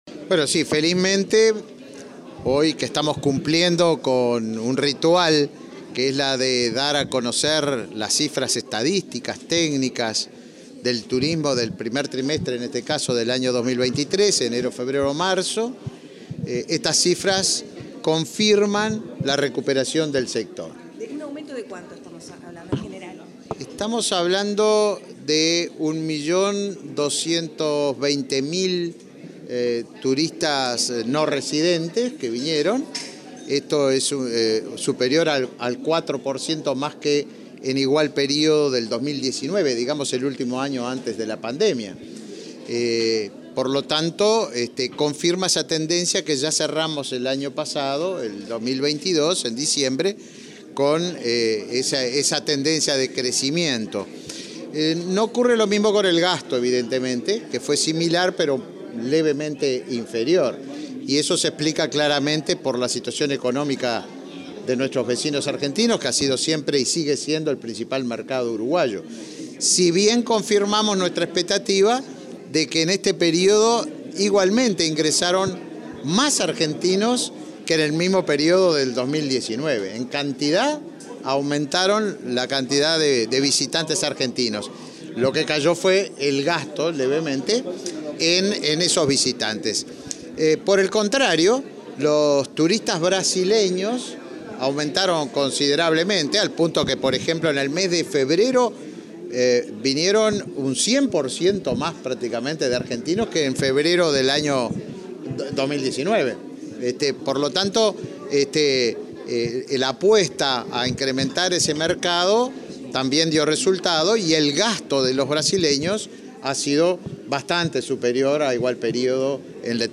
Declaraciones del ministro de Turismo, Tabaré Viera
Declaraciones del ministro de Turismo, Tabaré Viera 19/04/2023 Compartir Facebook X Copiar enlace WhatsApp LinkedIn El ministro de Turismo, Tabaré Viera, presentó este miércoles 19, en la sede de la cartera estatal, el balance del área de su competencia respecto al primer trimestre de 2023. Luego, dialogó con la prensa.